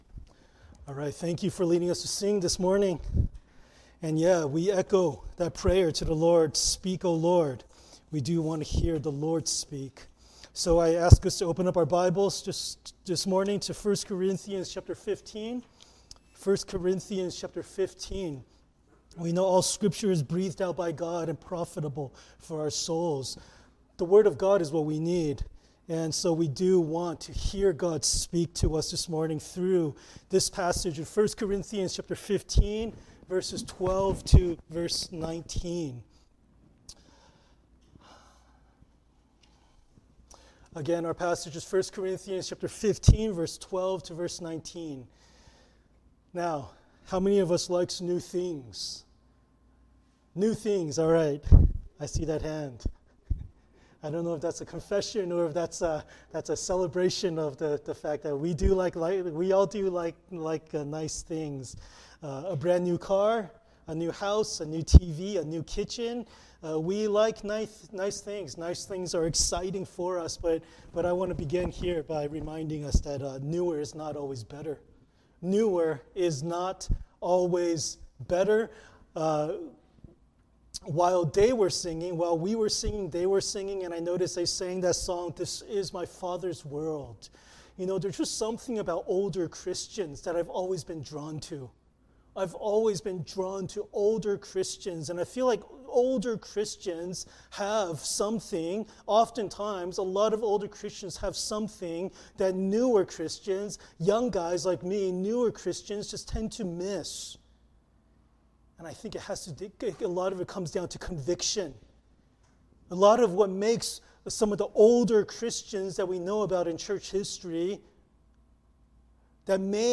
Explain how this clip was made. Passage: 1 Corinthians 15:12-19 Service Type: Sunday Worship